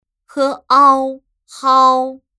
怎么读
hāo